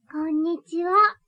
ボイス
女性挨拶